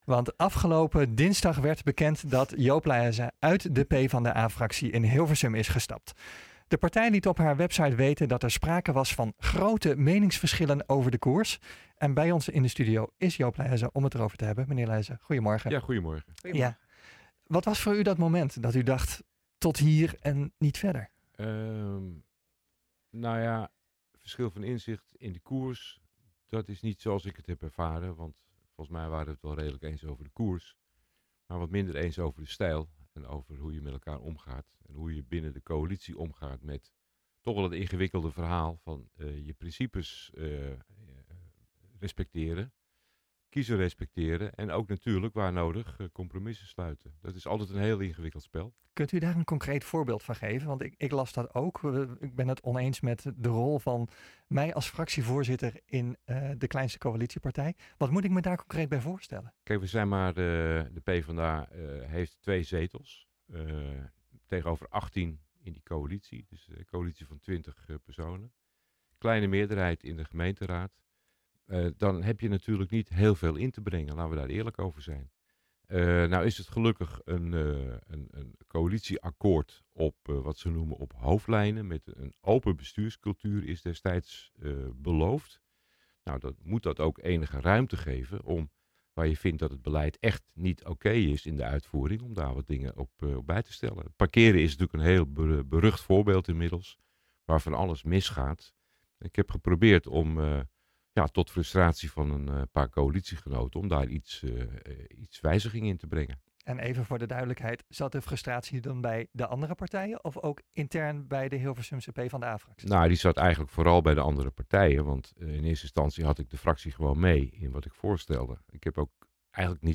Bij ons in de studio is Joop Lahaise om het hierover te hebben.
nh-gooi-zaterdag-fractievoorzitter-joop-lahaise-verlaat-pvda-gaat-zelfstandig-raadslid-verder.mp3